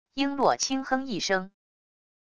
璎珞轻哼一声wav音频